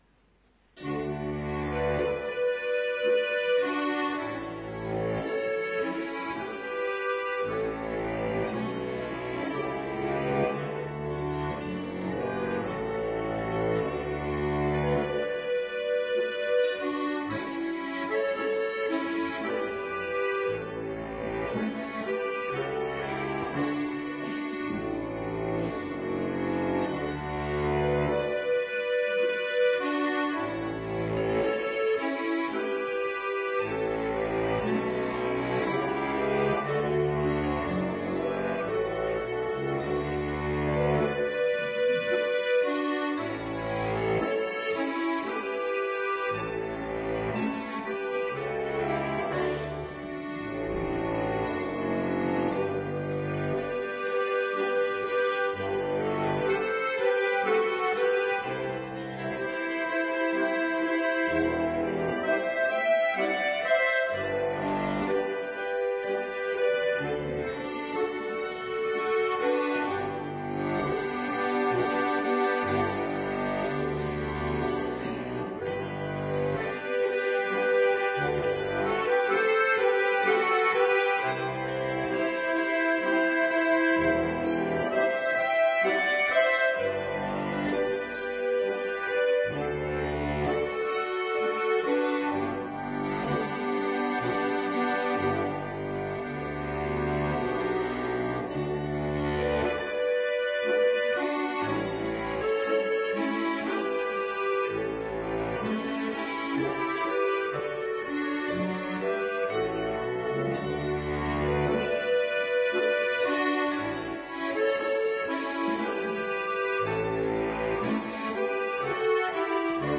Adventkonzert 2019
Am 1. Dezember hat der Advent gleich stimmungsvoll mit dem heurigen Adventkonzert begonnen.
Instrumentalmusik